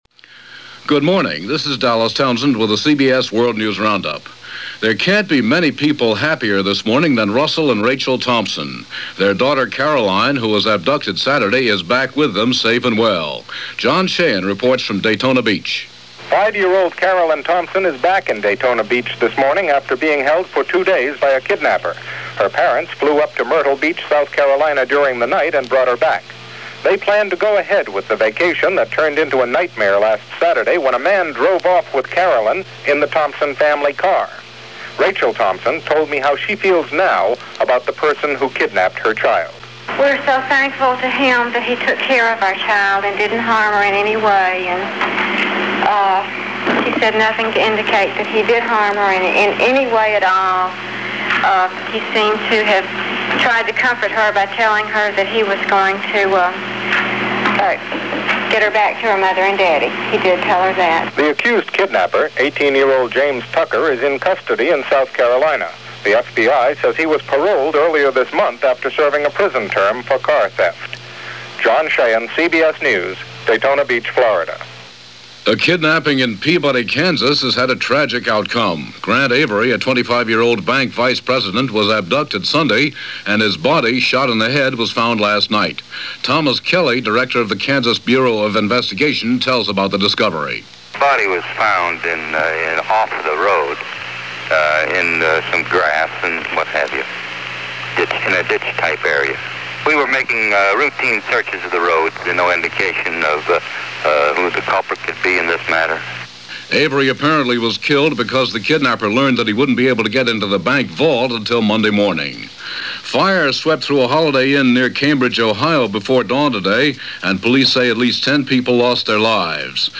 CBS World News Roundup